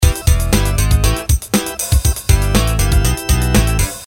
vzw_tune_unused.mp3